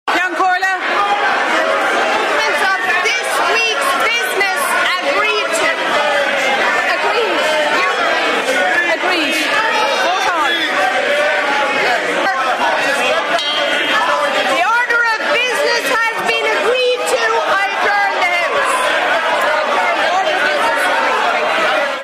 In a chaotic afternoon in the chamber, the combined opposition repeatedly questioned and shouted down Taoiseach Micheál Martin.
While Ceann Comhairle Verona Murphy, says TDs have been making a holy show of themselves.